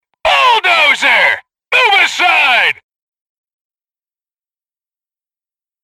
мужской голос
фраза